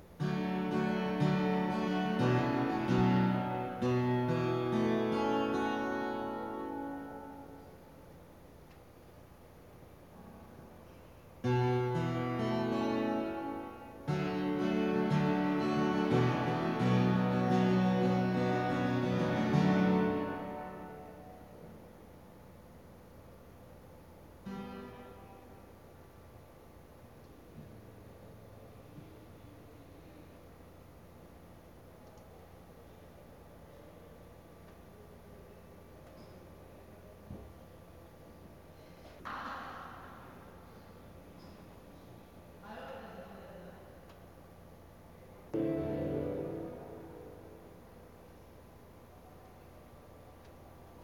acoustic duo
(soundcheck)